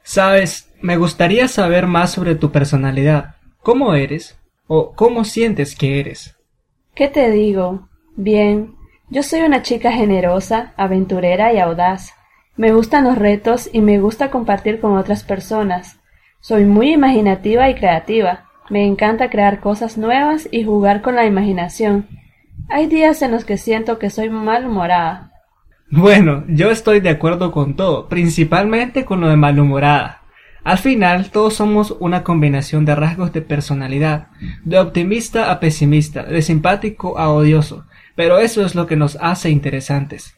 Completa el diálogo